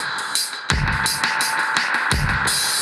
Index of /musicradar/dub-designer-samples/85bpm/Beats
DD_BeatFXA_85-01.wav